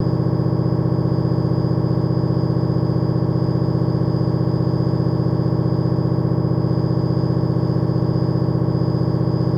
MRI Test Small Machine Reversed